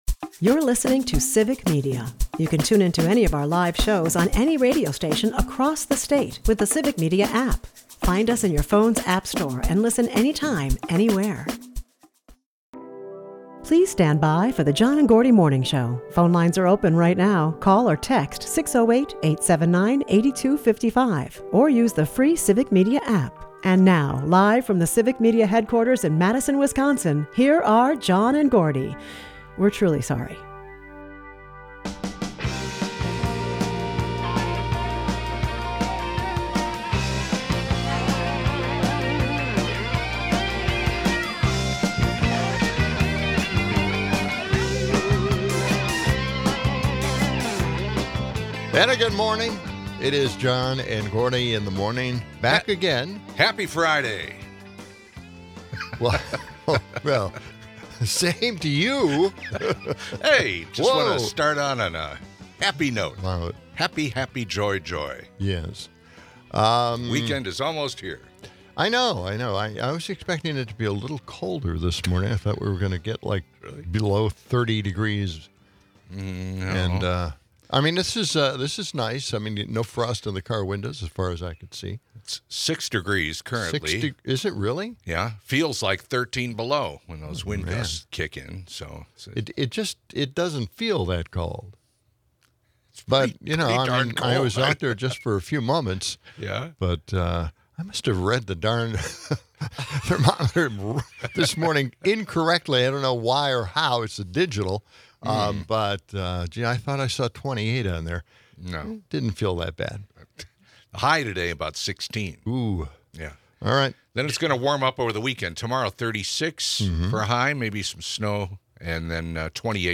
They humorously tackle listeners' calls, including one about Trump's fictitious war-ending claims. The show ends with the duo pondering the bizarre notion of Trump’s face on U.S. currency, painting a vivid picture of America’s current political landscape.